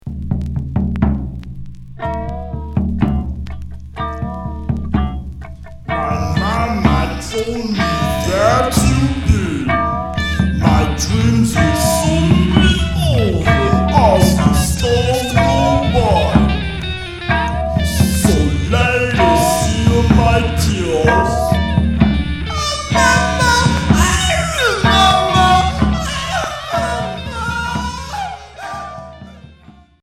Dada expérimental